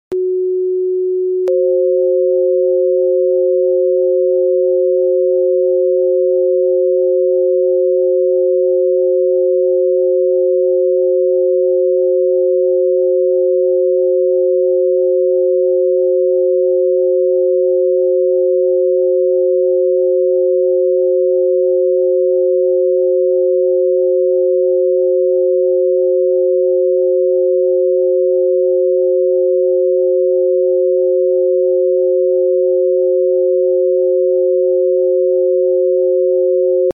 ✨ ℙ𝕌ℝ𝔼 369ℍ𝕫 ∞ 528ℍ𝕫 sound effects free download